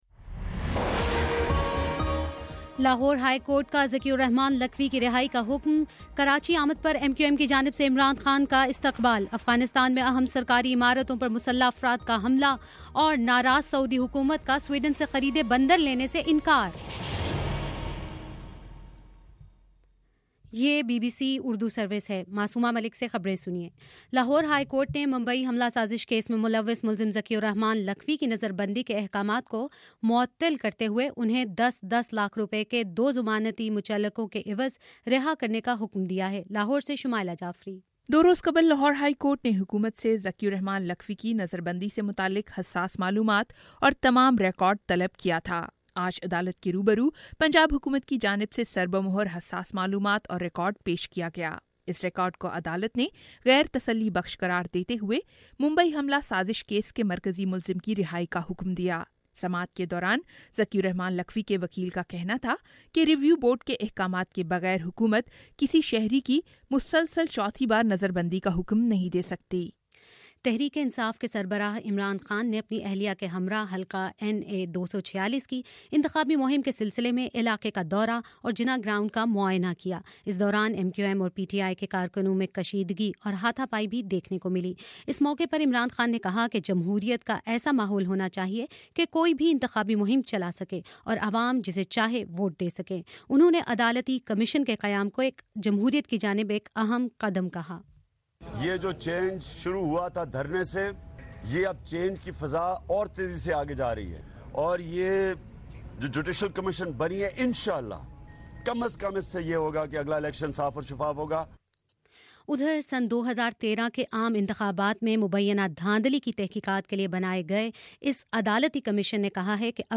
اپریل 9: شام سات بجے کا نیوز بُلیٹن